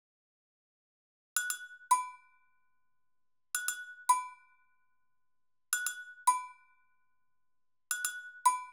30 Agogos.wav